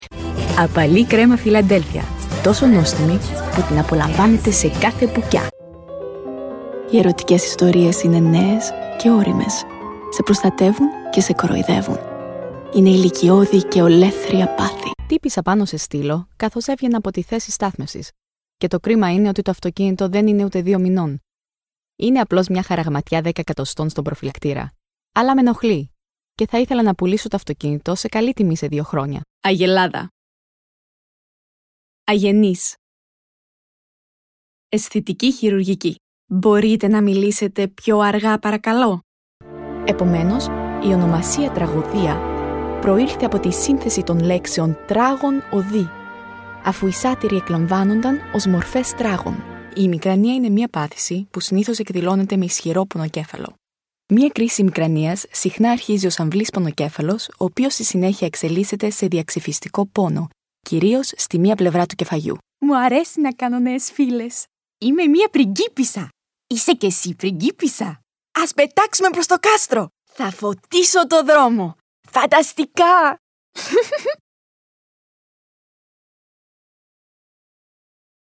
Greek Montage: